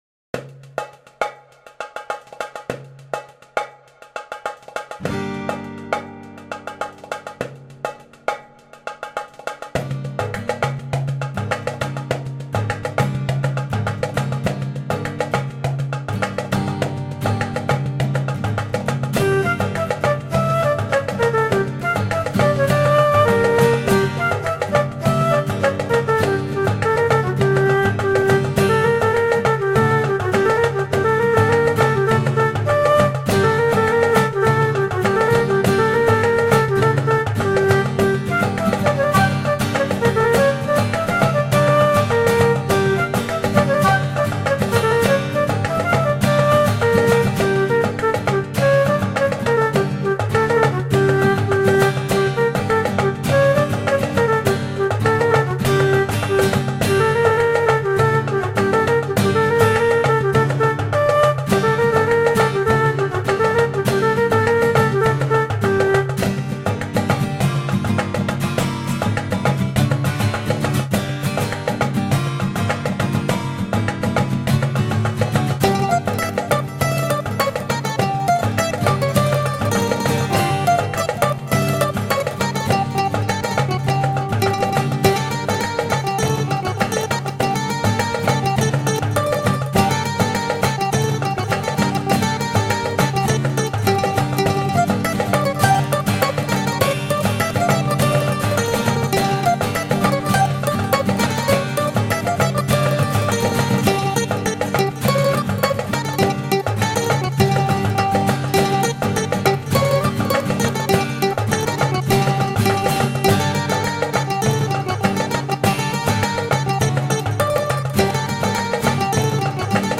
קובץ:ניגון ר' מענדל מהורודוק.mp3 – חב"דפדיה
עיבוד: ניצוצות של קדושה